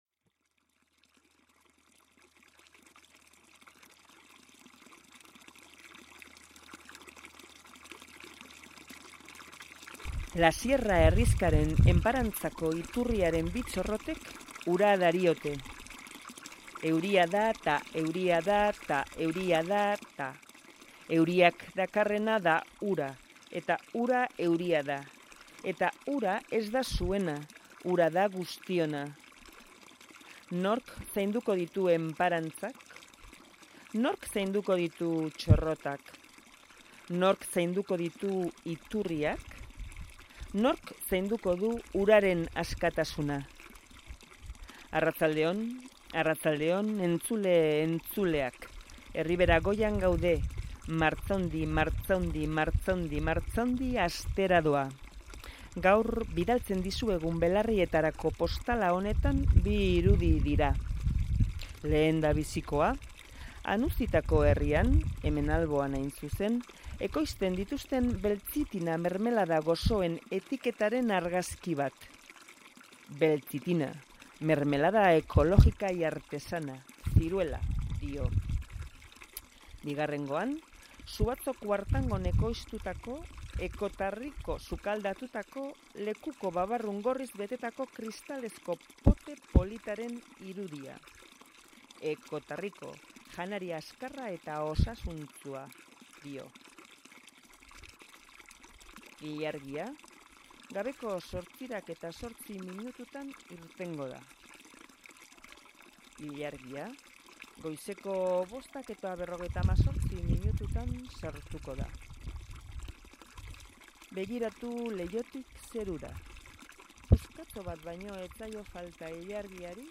Audio: Caminantes desde Azala a Azkuna Zentroa de Bilbao dentro del programa Prototipoak, experimentan con sonido andante. radio euskadi graffiti